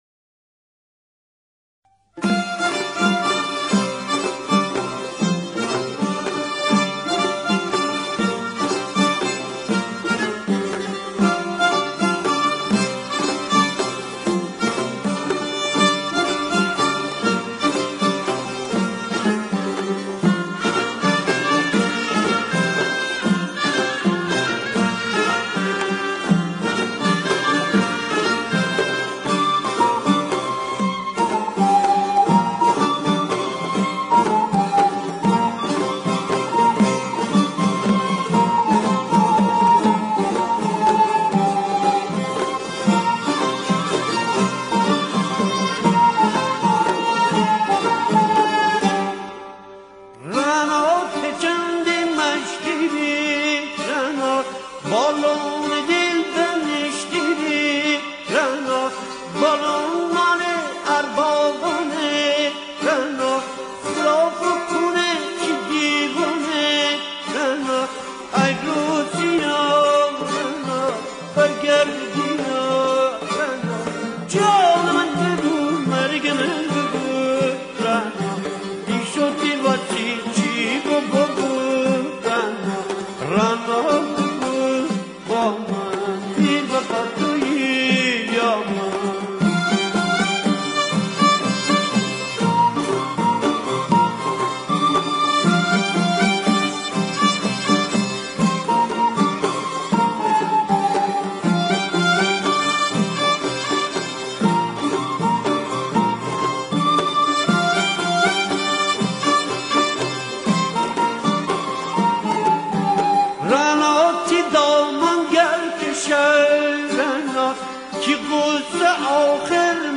ترانه گیلکی رعنا